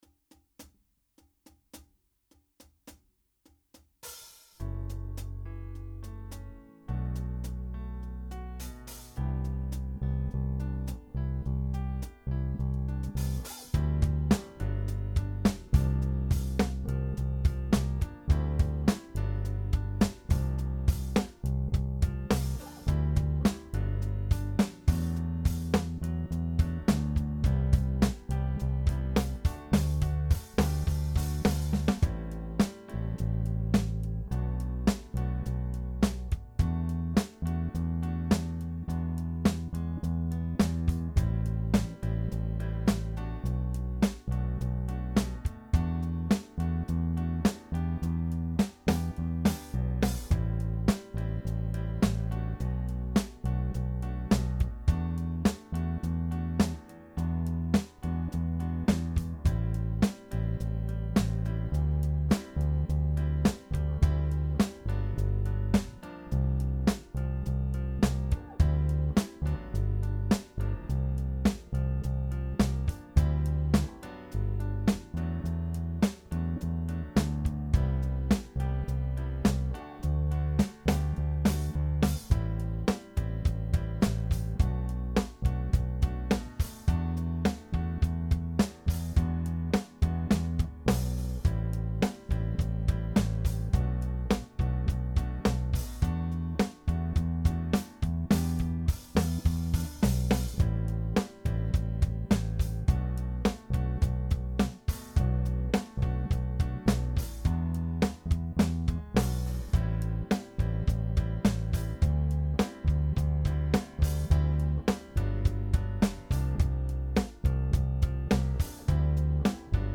Jam Track - 105 BPM
Cover version